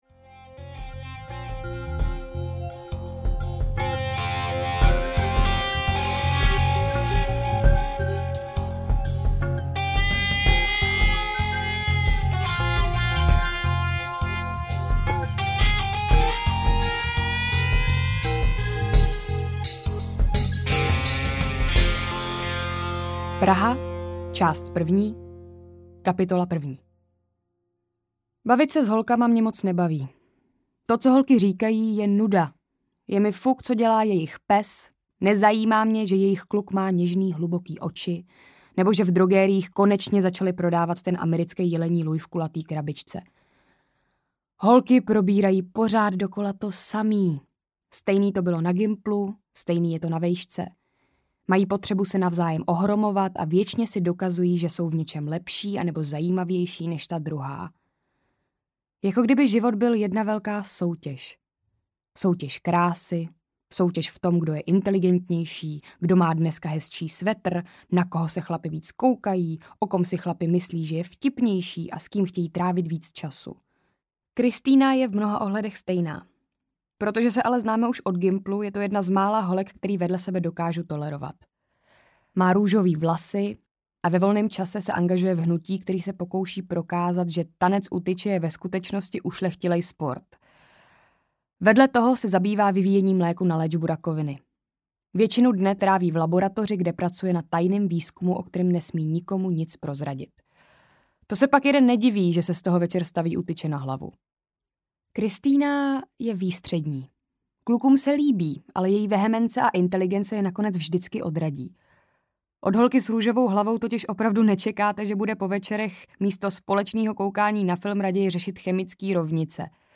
Probudím se na Šibuji audiokniha
Ukázka z knihy